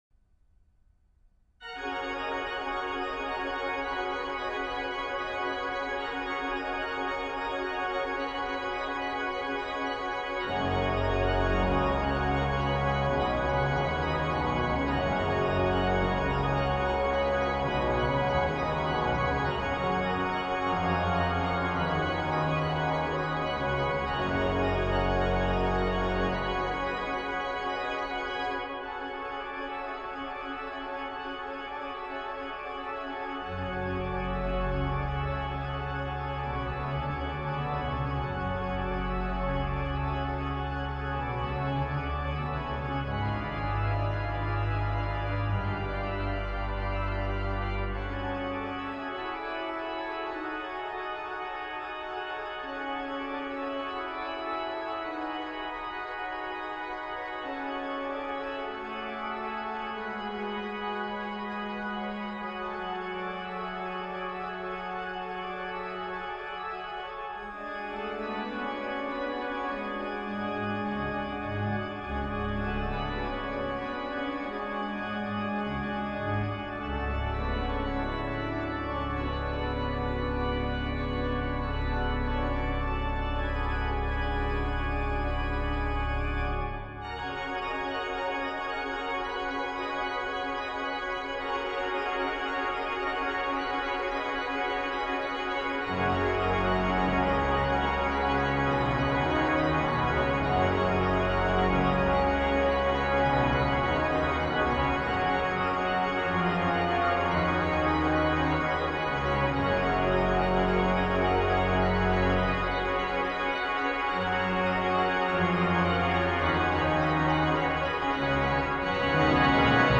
Voicing: Organ 3-staff